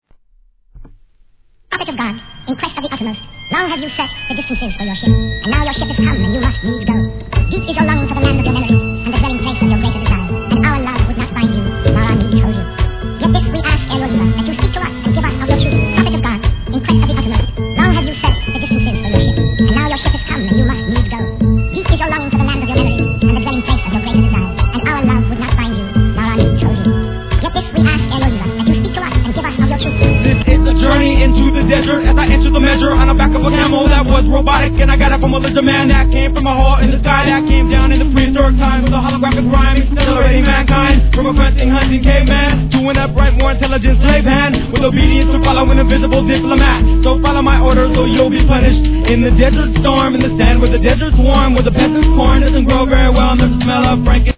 1. JAPANESE HIP HOP/R&B